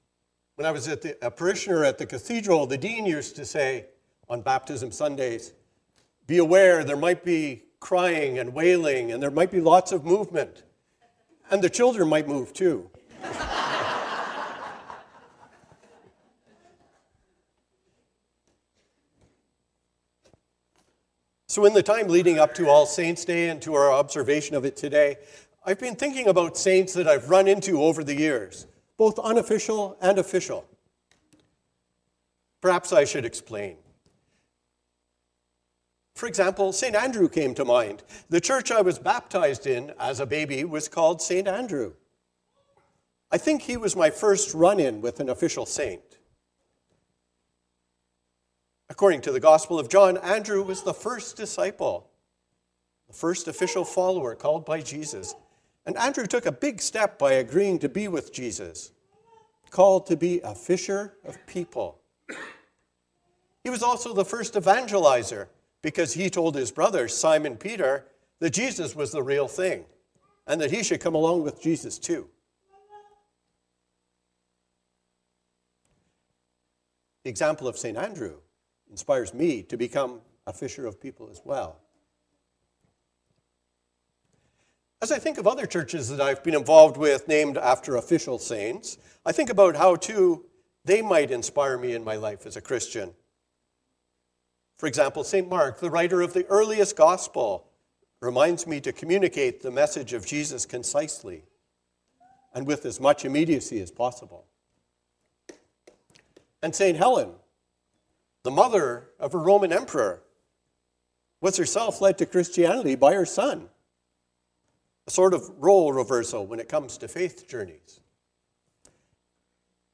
A sermon for All Saints and the celebration of baptism.